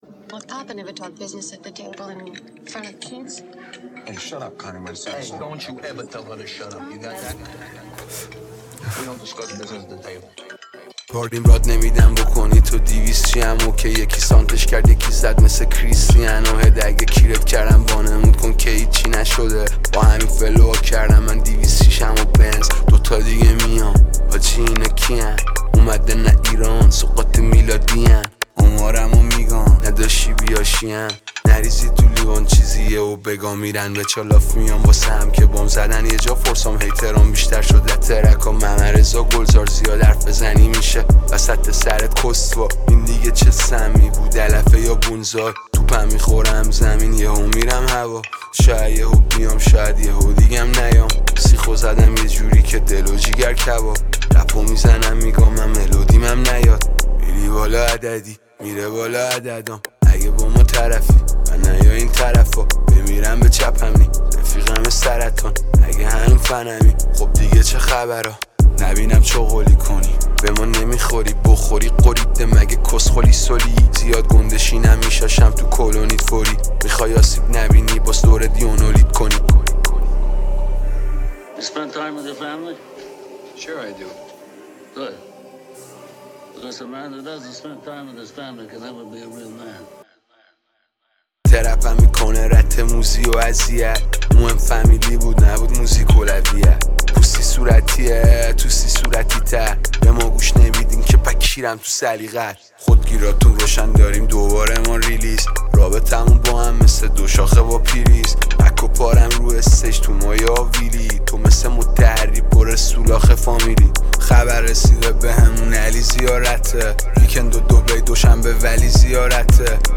رپر
یه ترک دوپس دوپسی و جذاب